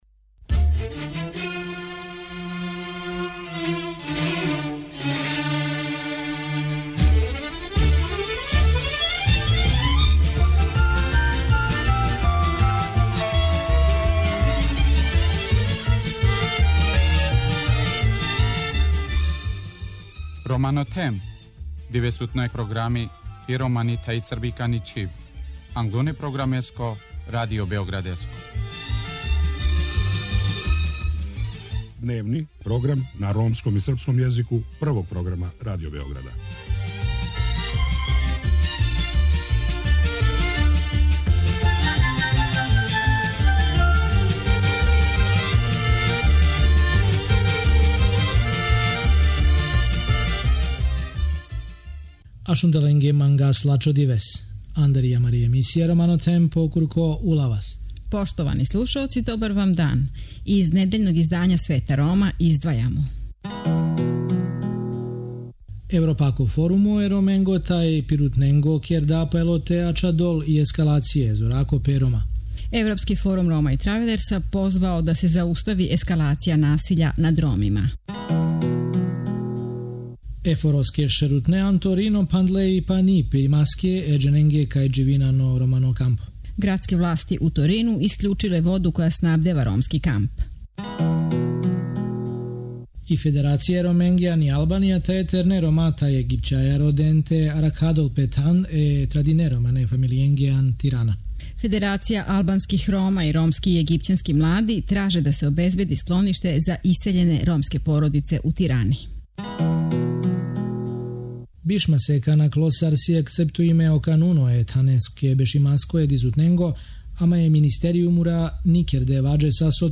Чућемо и утиске са Гоча из Летњег кампа "Корачајмо заједно".